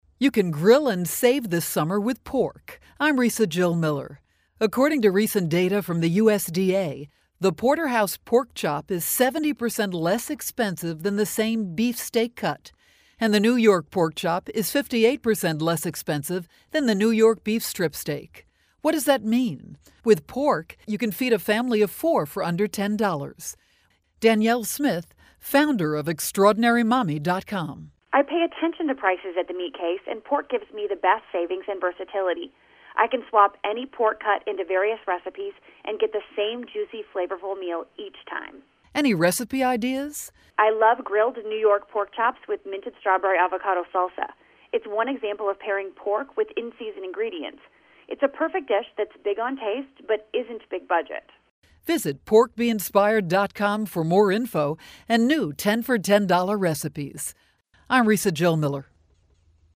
July 15, 2013Posted in: Audio News Release